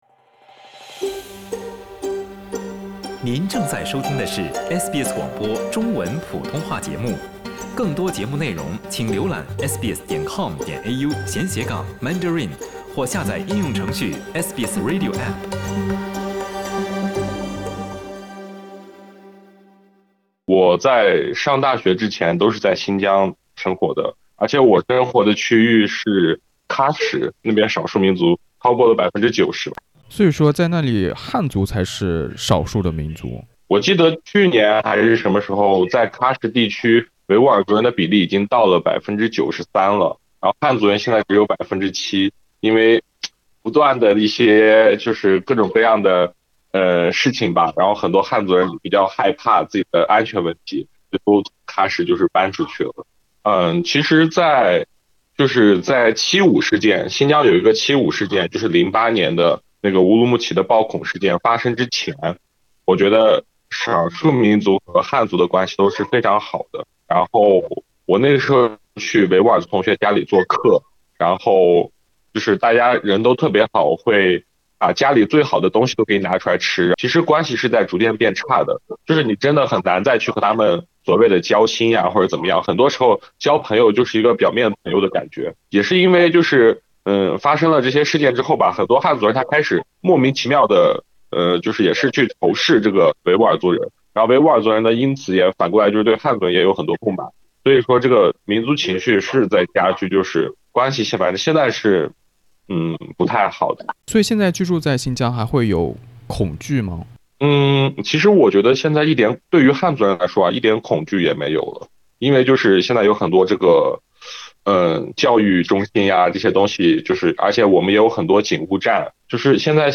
点击收听新疆见闻： LISTEN TO 一名新疆汉族的回乡见闻：“汉族的‘人权’也是人权” SBS Chinese 08:32 cmn *应采访人要求，文中使用化名，声音经过处理。